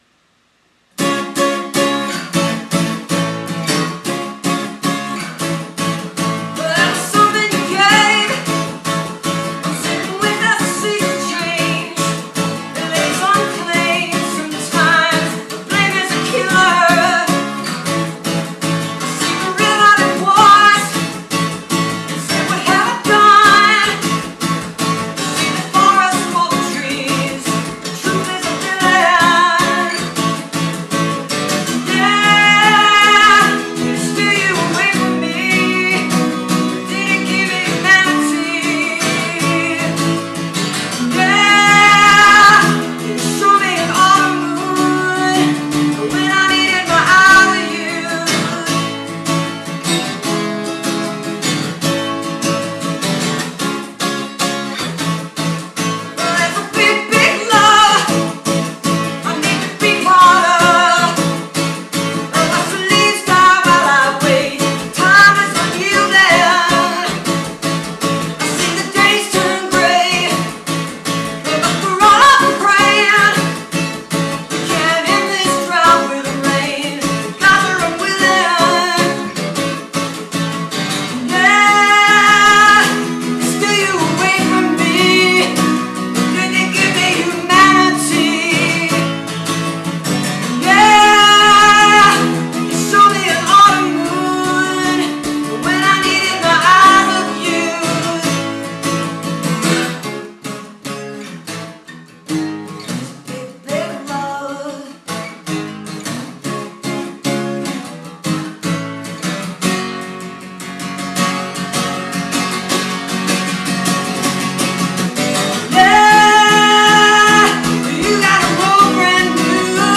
2008. all the performances are acoustic